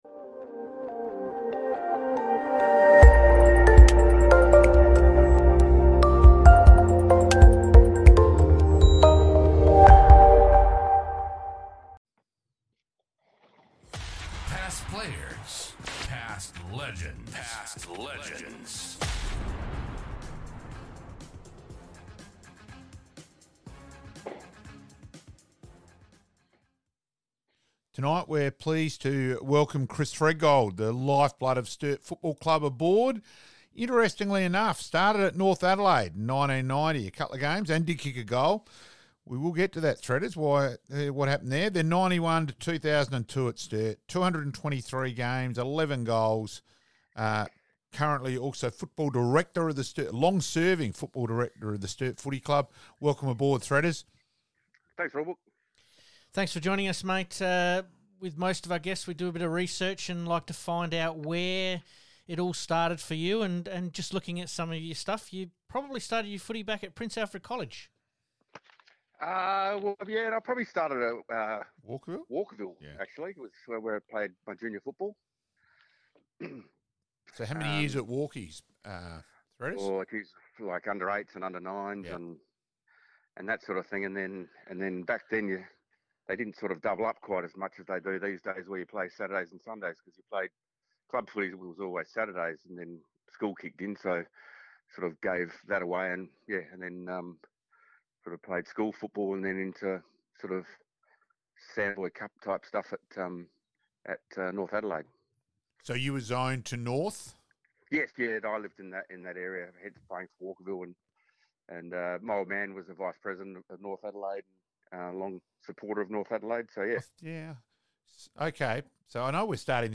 FLASHBACKS - Re-Live some of our Interviews (only) with some of our special guests